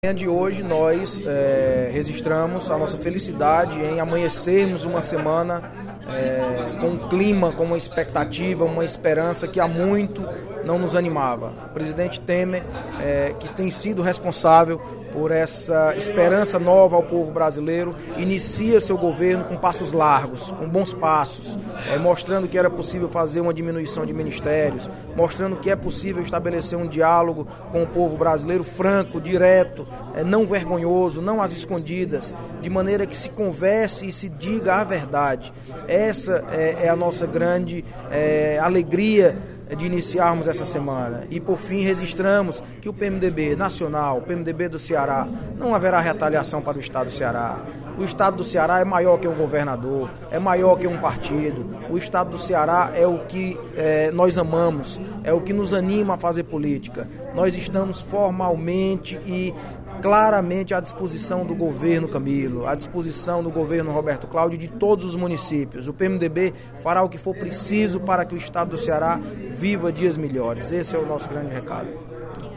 O deputado Audic Mota, líder do PMDB, parabenizou, no primeiro expediente da sessão plenária desta terça-feira (17/05), o presidente interino, Michel Temer, que assumiu o Governo Federal na quinta-feira (12/05), depois do afastamento da presidente, Dilma Rousseff, pelo Congresso Nacional.